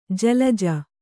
♪ jalaja